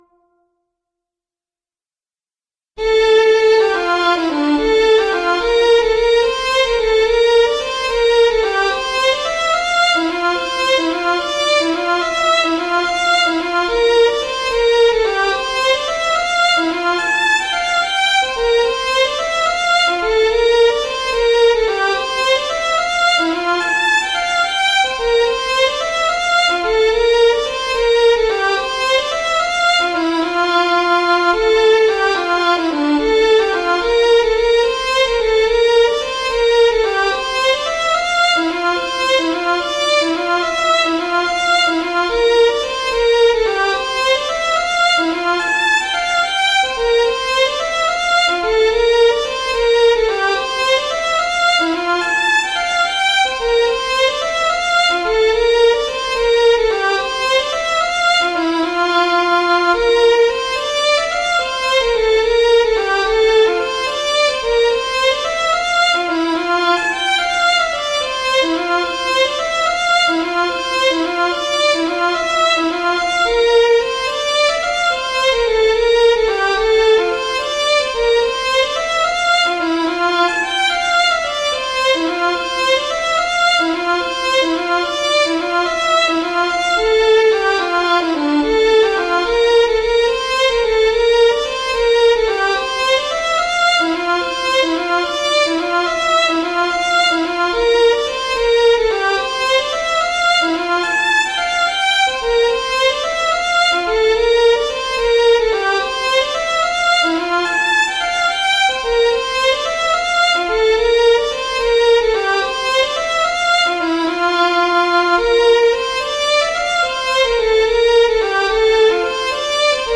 We had some down time while loading one of our servers, so I fired up MuseScore, started with a new score, put in a couple of notes, and bam!
This is in the key of F major (yeah, I know, a MAJOR key - what's become of him?) and it's a nice happy tune.  It make me start tapping my feet when I was listening to it.